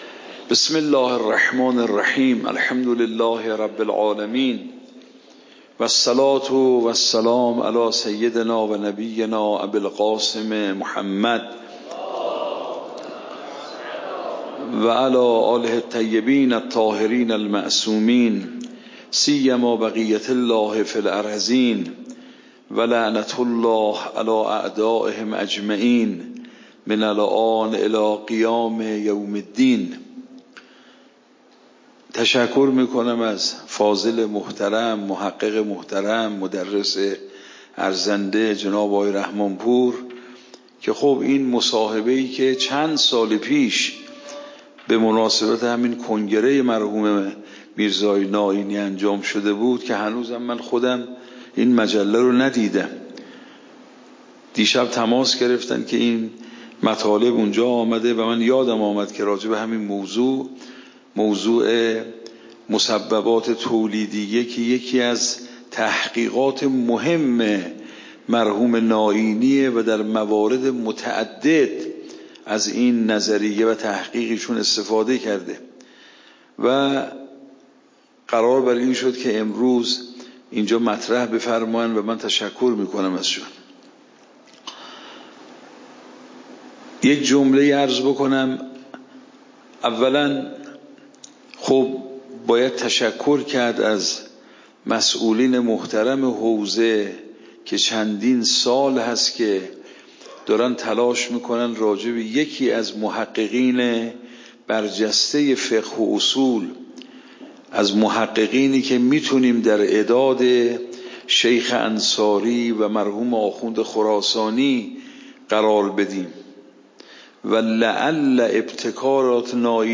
درس بعد واجب نفسی و غیری درس قبل واجب نفسی و غیری درس بعد درس قبل موضوع: واجب نفسی و غیری اصول فقه خارج اصول (دوره دوم) اوامر واجب نفسی و غیری تاریخ جلسه : ۱۴۰۴/۸/۳ شماره جلسه : ۲۵ PDF درس صوت درس ۰ ۱۱۰